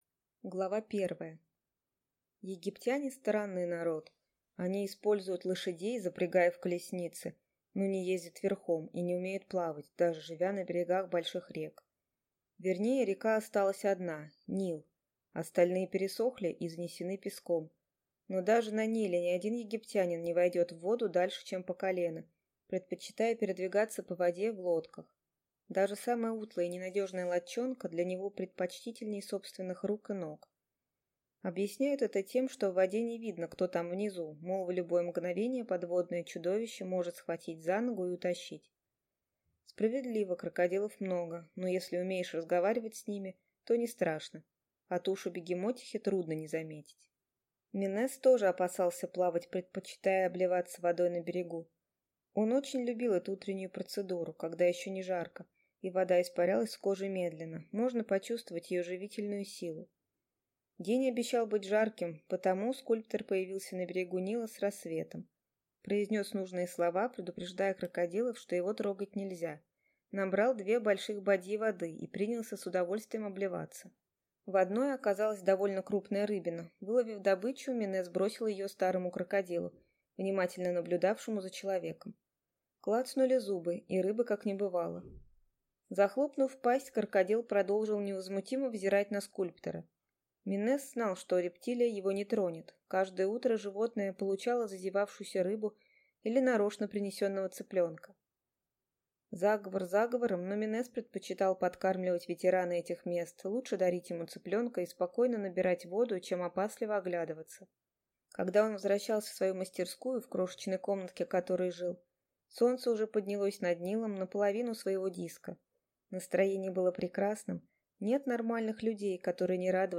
Аудиокнига Боги Египта | Библиотека аудиокниг